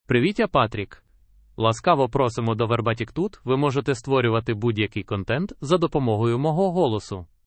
Patrick — Male Ukrainian AI voice
Patrick is a male AI voice for Ukrainian (Ukraine).
Voice sample
Listen to Patrick's male Ukrainian voice.
Patrick delivers clear pronunciation with authentic Ukraine Ukrainian intonation, making your content sound professionally produced.